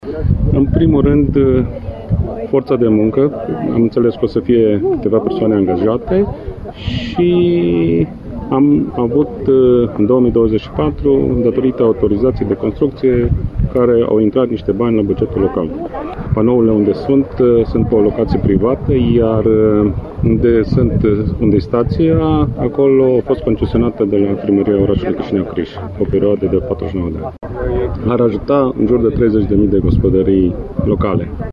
Primarul municipiului Chișineu Criș, Ovidiu Guleș, amintește alte două avantaje ale investiției fotovoltaice.